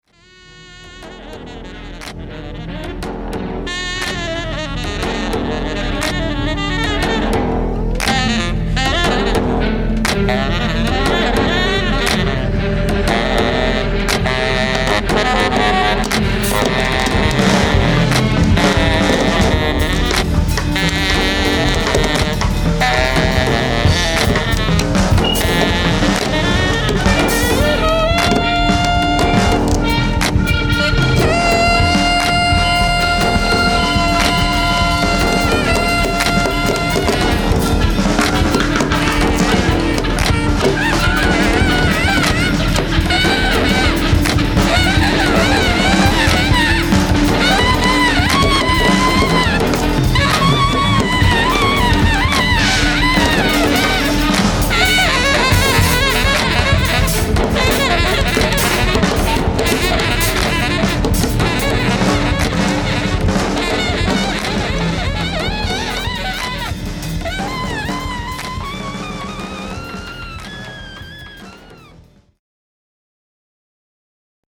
tenor saxophone